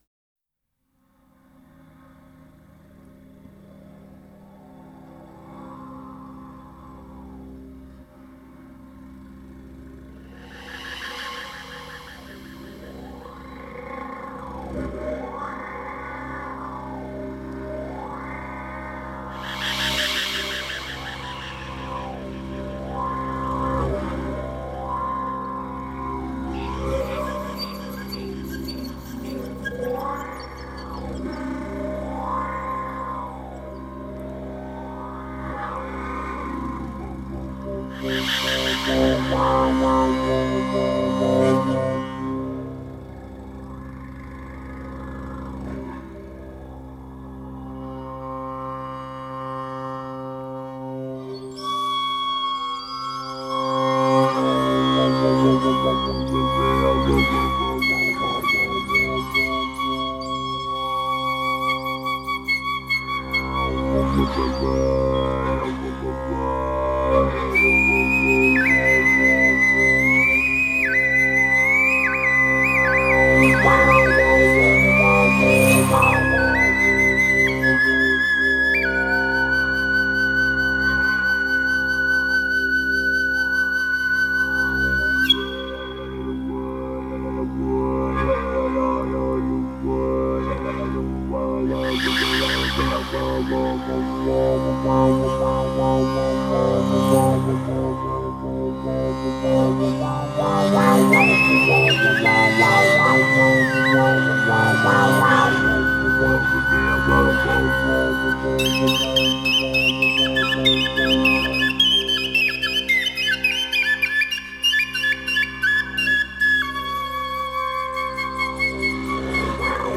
Genre: World Music
Flute [Eagle Feather Flute]
Recording: Windwood Studios